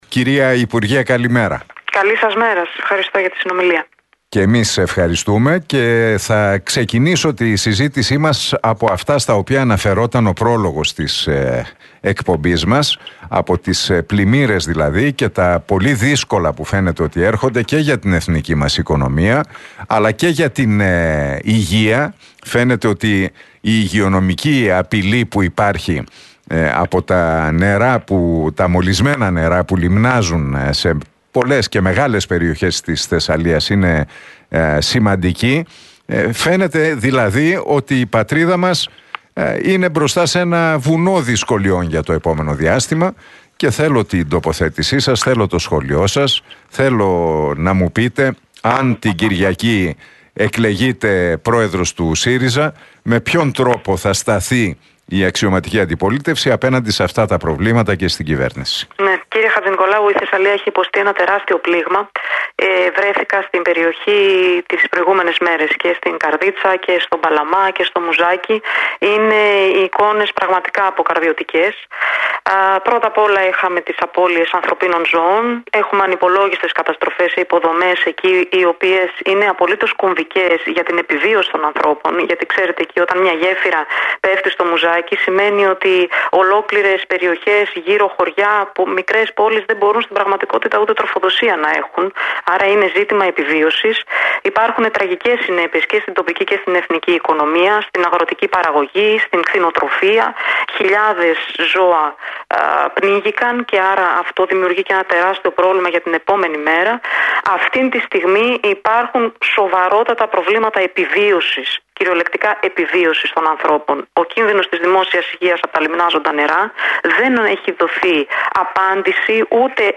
Η υποψήφια για την προεδρία του ΣΥΡΙΖΑ, Έφη Αχτσιόγλου, μίλησε στην εκπομπή του Νίκου Χατζηνικολάου στον Realfm 97,8.